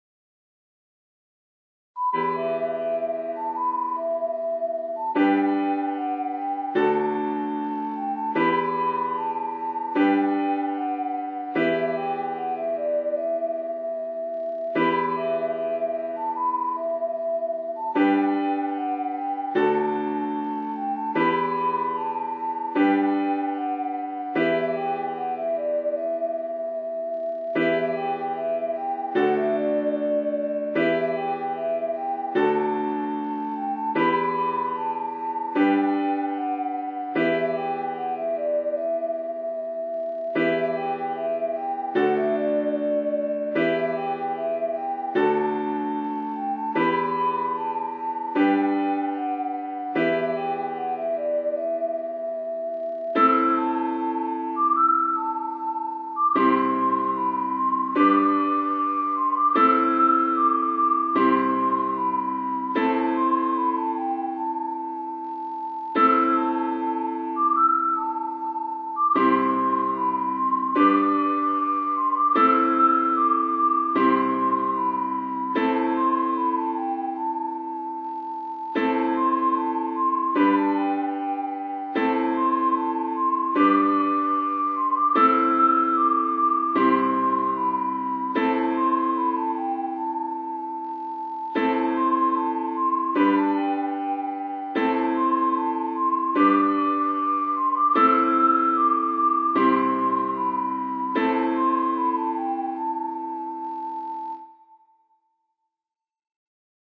BG: Bear Dance Piano (Tempo 75%) MP3
AH_BG_Bear_Dance_Piano_75_TIN.mp3